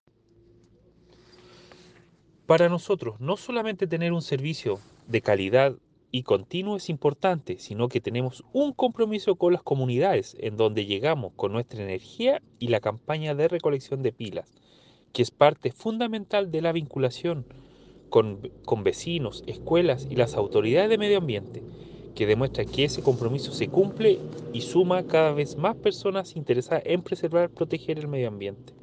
La actividad se realizó en el jardín infantil “Raíces de mi pueblo” en la comuna de Paillaco.